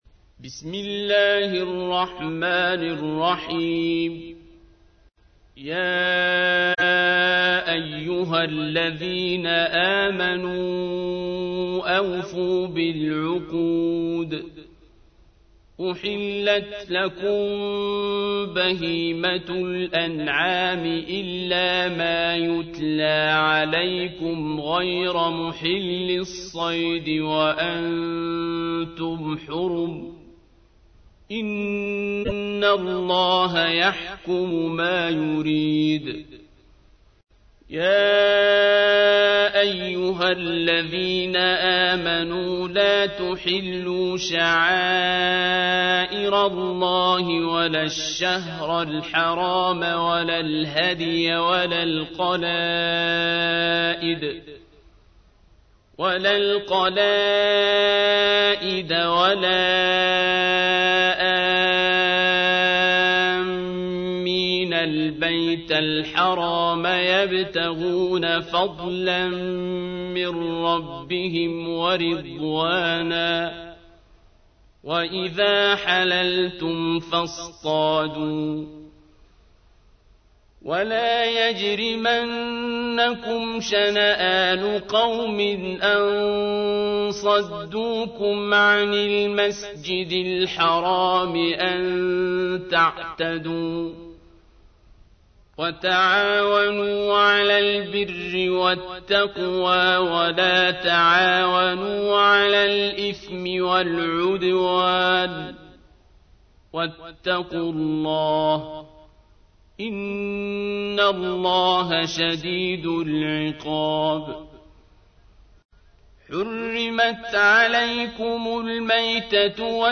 تحميل : 5. سورة المائدة / القارئ عبد الباسط عبد الصمد / القرآن الكريم / موقع يا حسين